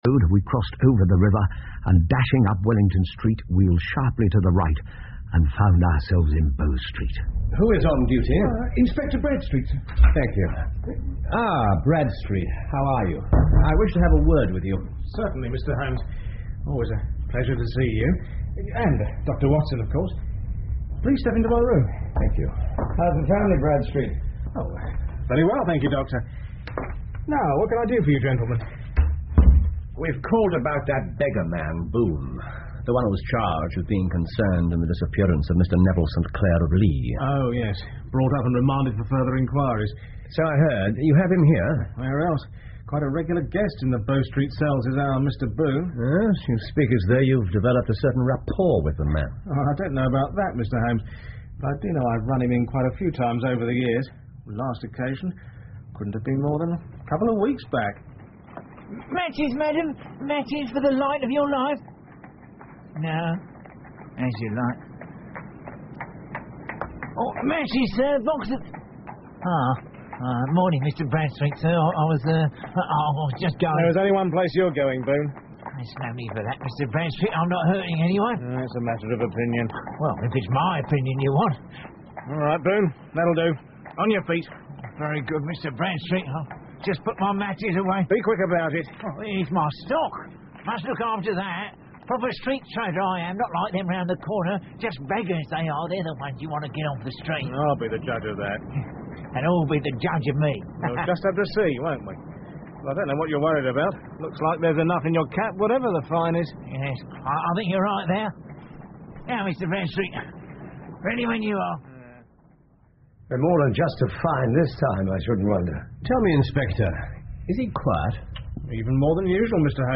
福尔摩斯广播剧 The Man With The Twisted Lip 7 听力文件下载—在线英语听力室